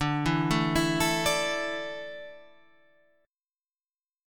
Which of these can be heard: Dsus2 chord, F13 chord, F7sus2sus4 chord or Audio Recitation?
Dsus2 chord